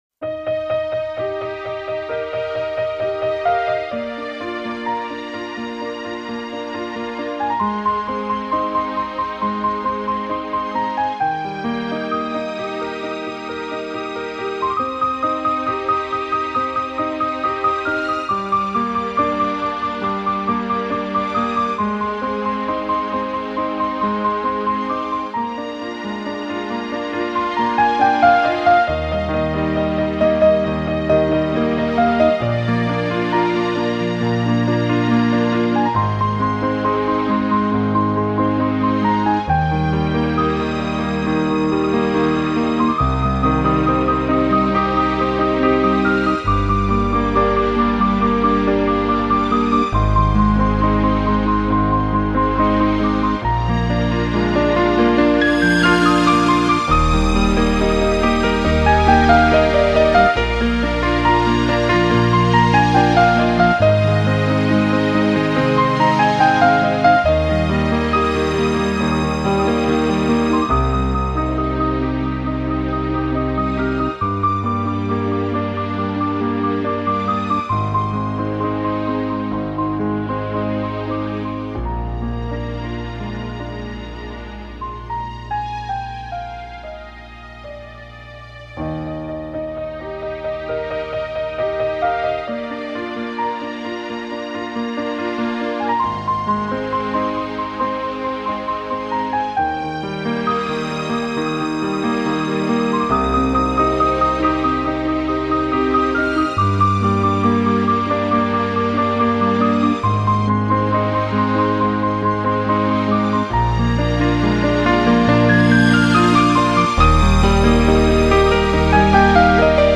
Genre:New Age, Instrumental, Neo-Classical, Piano